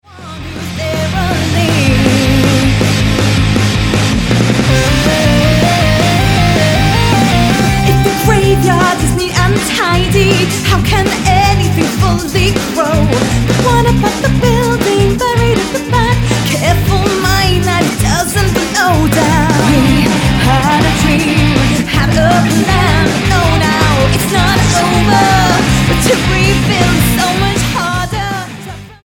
Pop punk band
Style: Rock